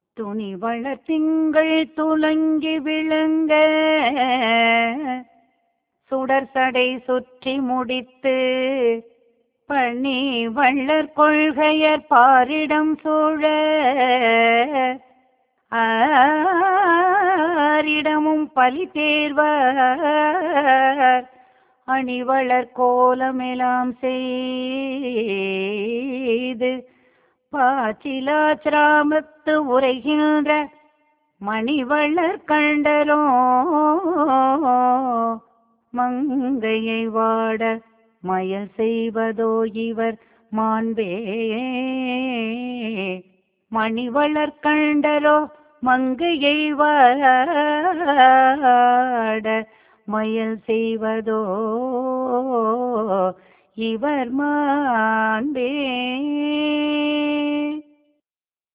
பண்: தக்கராகம்